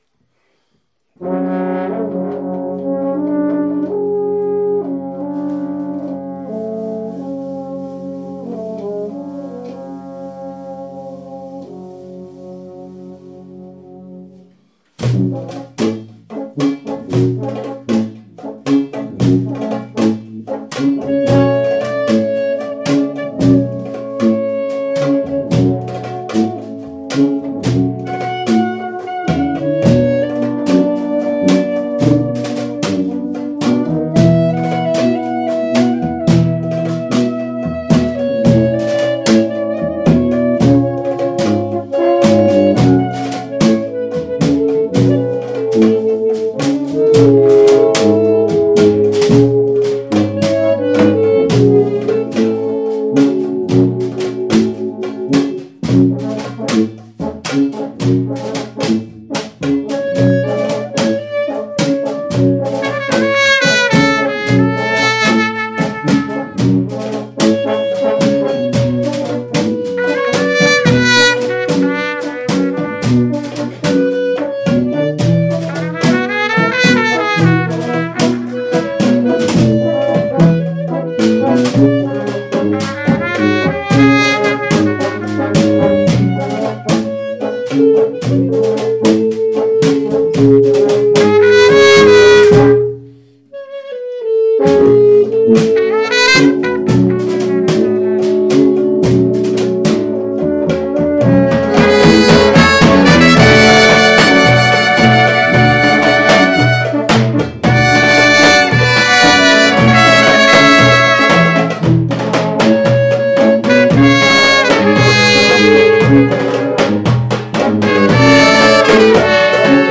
Inspector Gadje Rehearsal Recordings